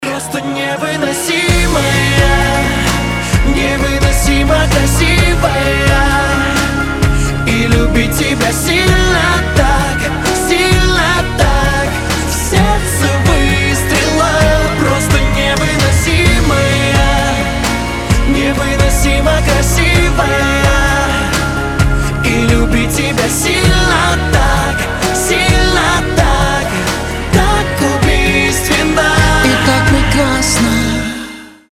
• Качество: 320, Stereo
поп
мужской вокал
романтические